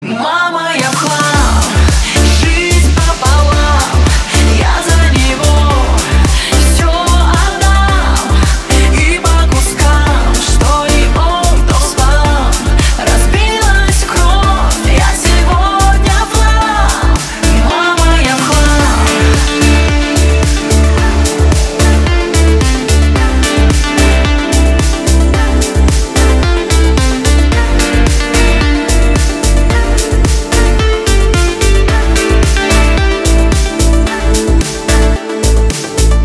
• Качество: 320, Stereo
поп
женский вокал
deep house